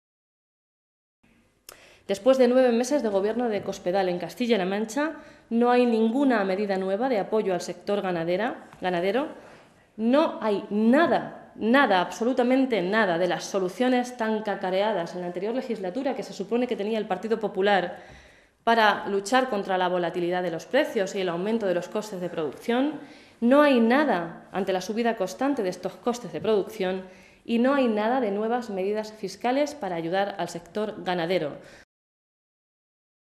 Blanca Fernández, portavoz de Agricultura del Grupo Parlamentario Socialista
Cortes de audio de la rueda de prensa